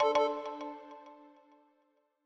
Longhorn 8 - Hardware Fail.wav